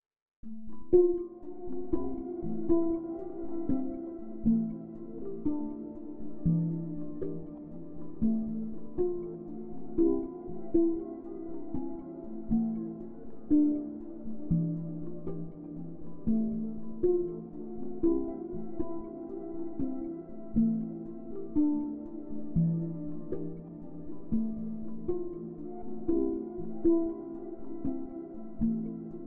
It's pretty good at string-like and marimba-like sounds and pairs well with a dash of reverb after it.
Impulse mode paired with a reverb
partials_impulse_pan_feedback.mp3